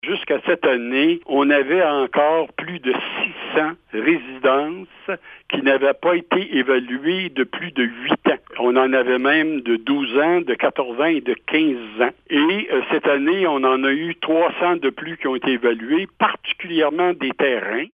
Sa valeur uniformisée sur le territoire est passée d’environ 117 millions à 175 millions $. Une mise à jour a en effet été effectuée cette année et de nombreuses propriétés ont été évaluées, explique le maire de Denholm, Gaétan Guindon :